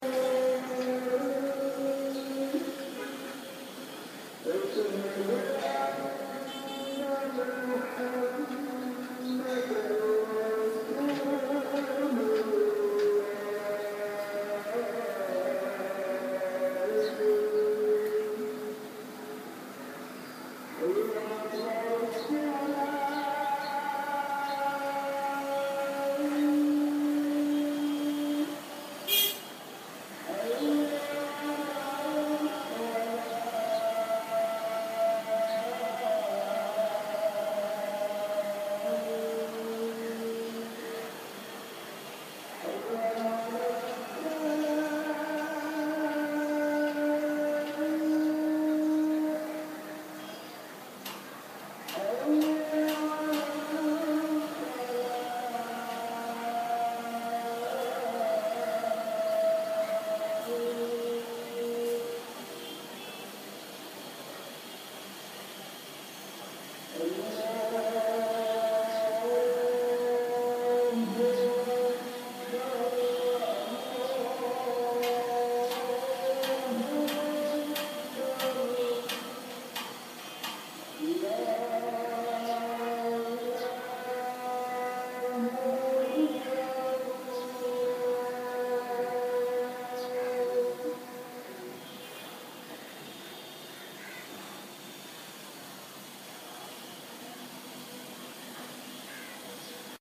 Call to prayer, Dhaka, Bangladesh